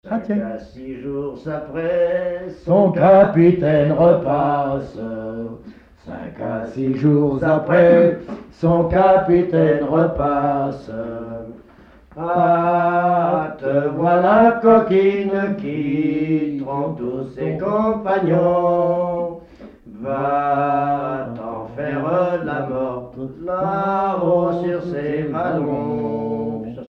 Verchaix
Pièce musicale inédite